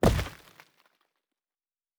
Stone 07.wav